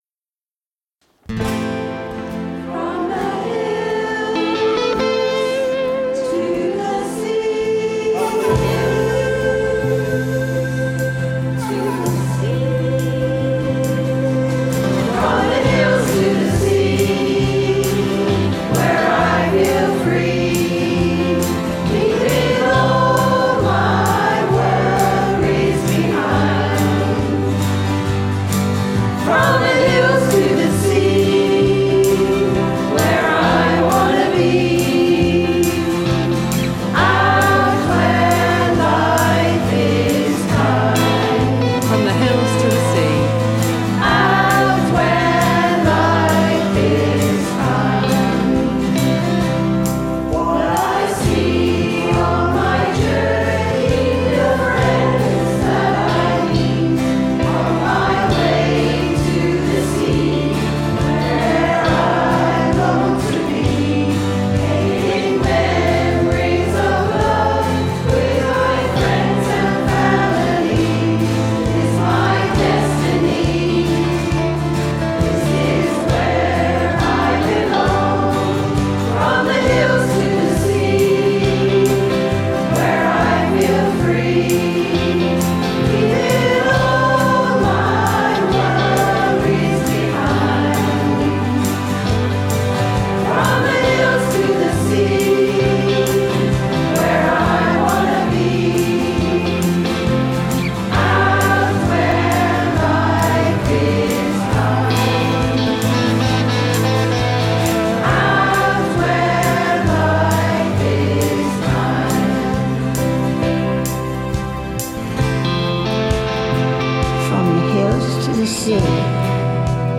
The group wrote the words and music together and have recorded a wonderful song they would like to share with everyone.